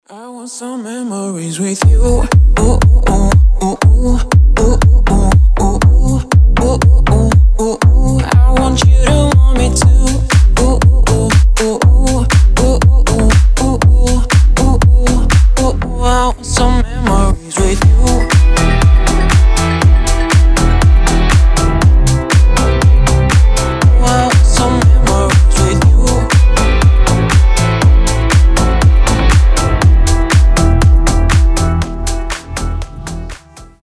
громкие
заводные
Dance Pop
басы
house